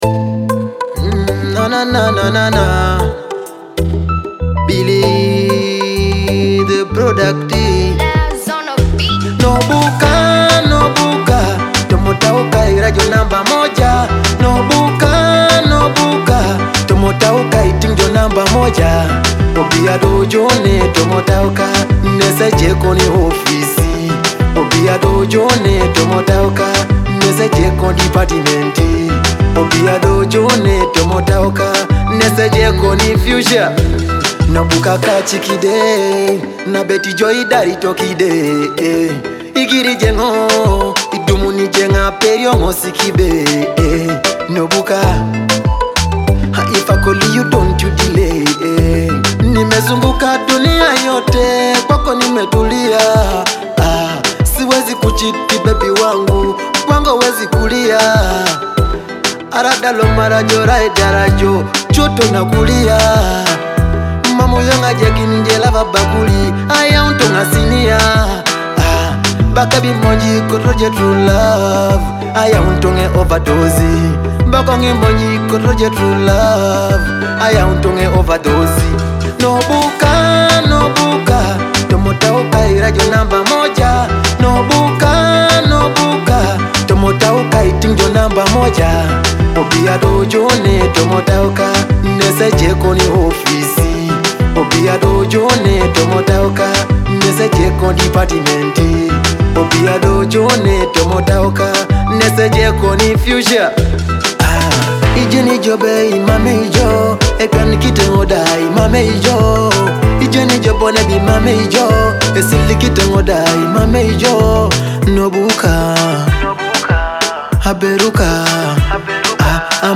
Download the latest Teso love song